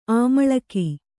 ♪ āmaḷaki